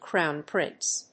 アクセントcrówn prínce
音節cròwn prínce